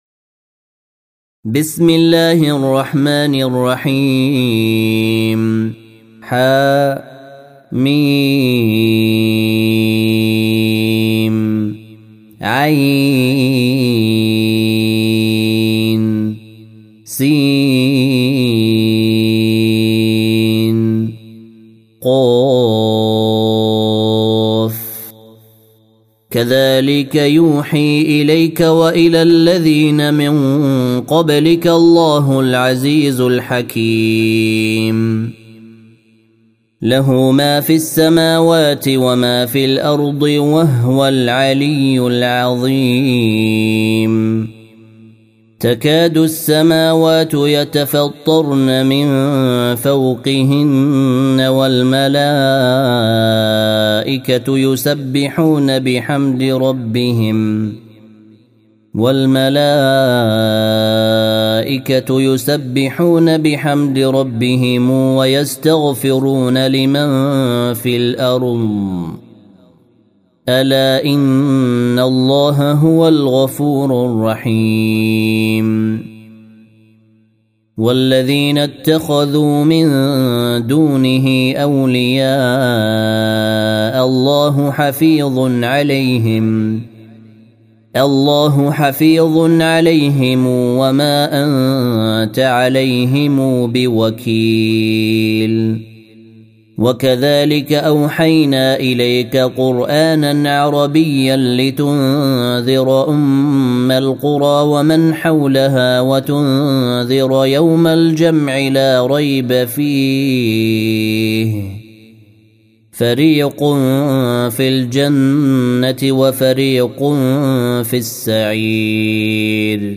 جودة عالية